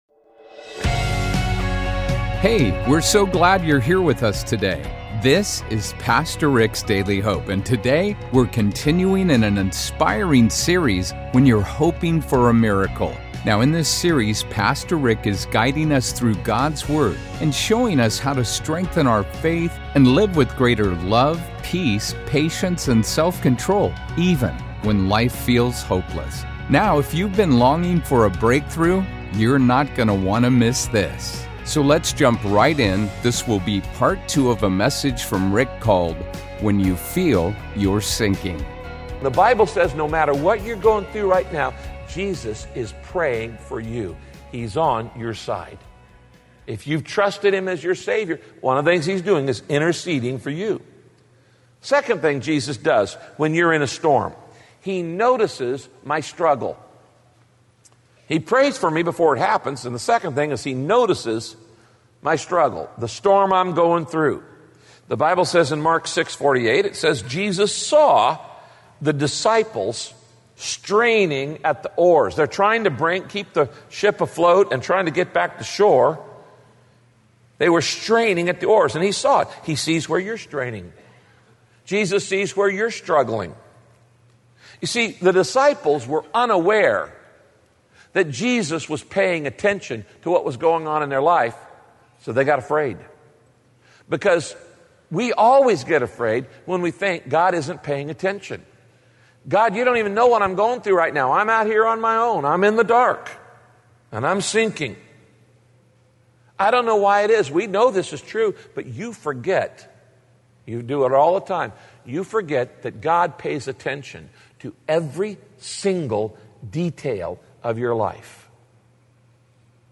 In this message, Pastor Rick teaches how Jesus comes at your moment of desperation and shows his true identity when you're facing the storms of life.